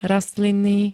Zvukové nahrávky niektorých slov
wmsv-rastlinny.spx